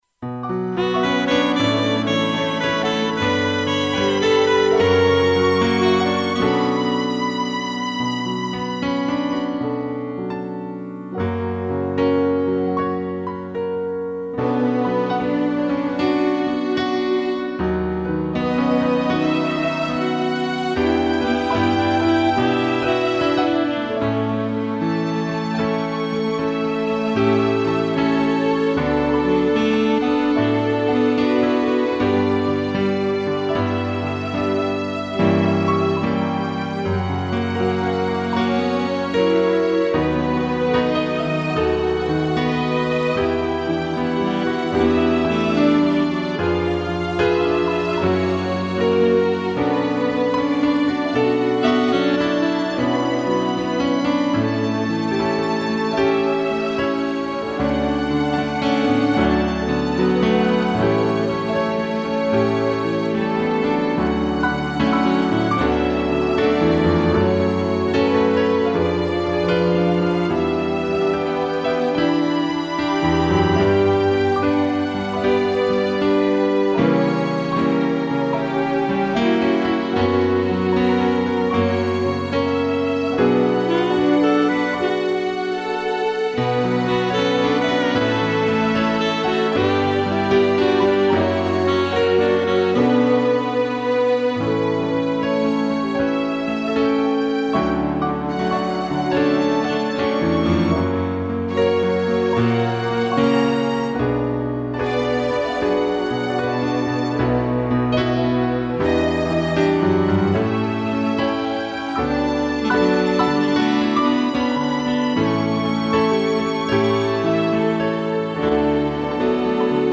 2 pianos, violino, cello e sax
(instrumental)